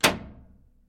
На этой странице представлена коллекция звуков рубильника — от четких металлических щелчков до глухих переключений.
Звук рубильника - альтернативный вариант